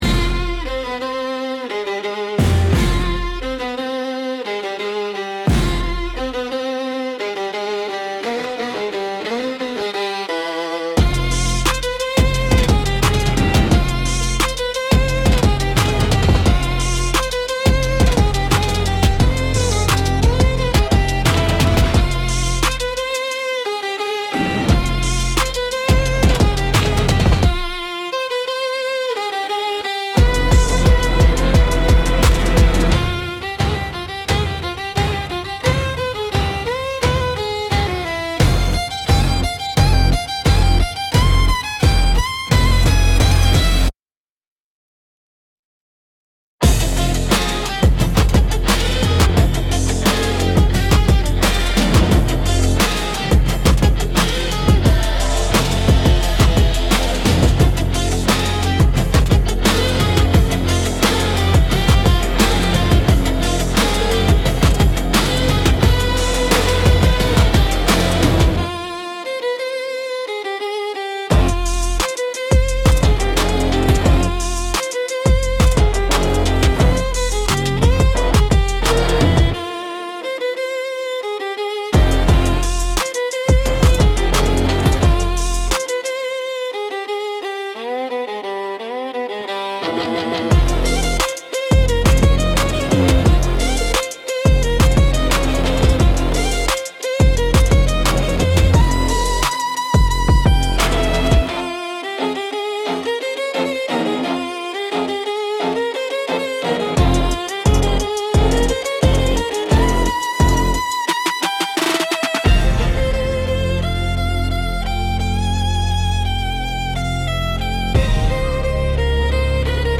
Instrumental - Moonlight Dares Me